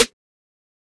Rolling Snare.wav